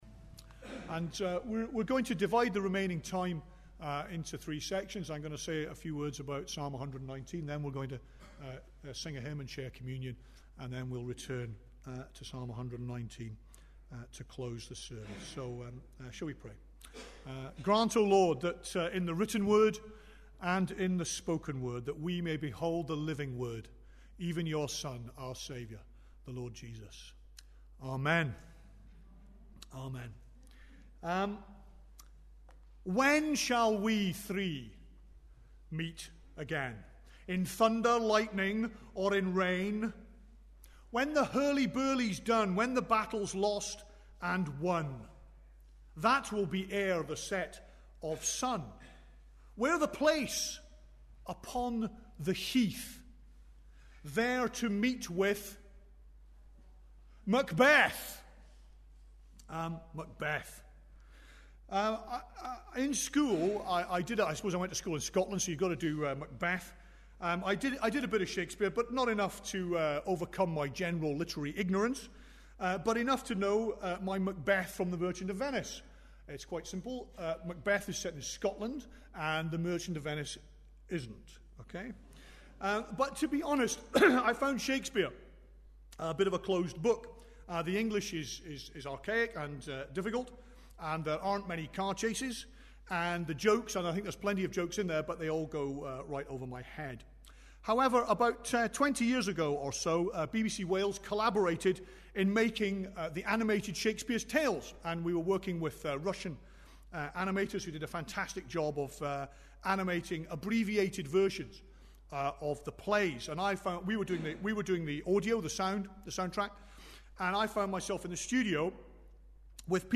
Unspecified Passage: 119:17-32 Service Type: Sunday Morning Bible Text